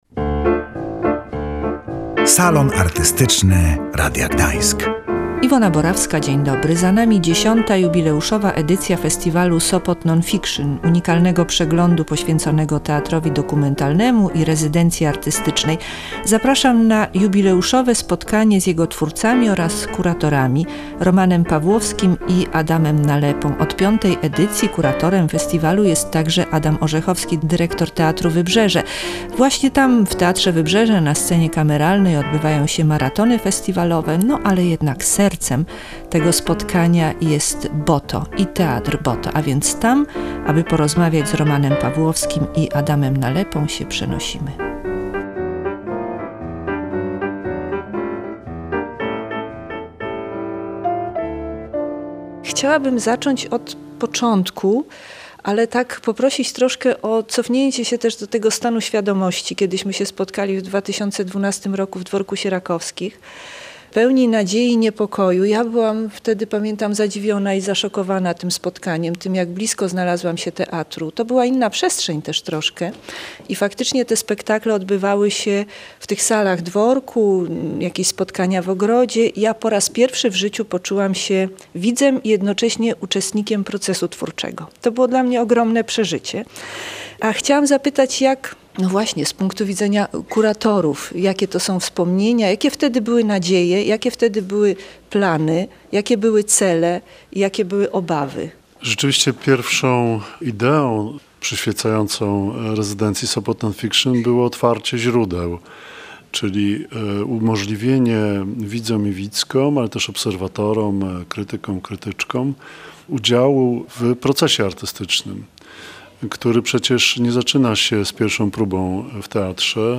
X Festiwalu Sopot Non Fiction. Jubileuszowa rozmowa